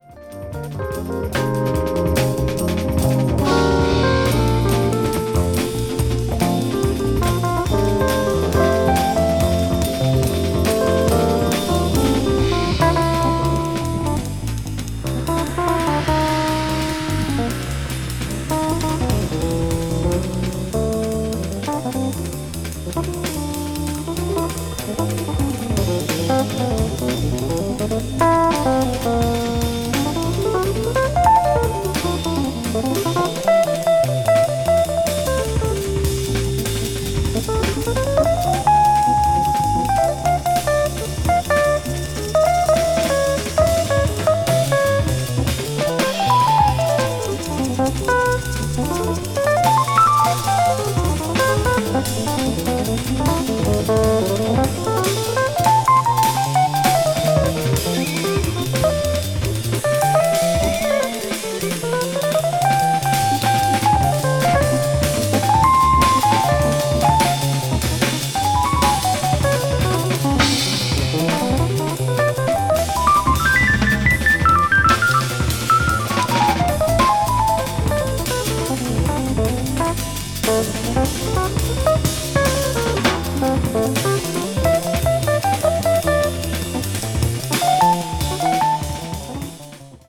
contemporary jazz   crossover   fusion   spiritual jazz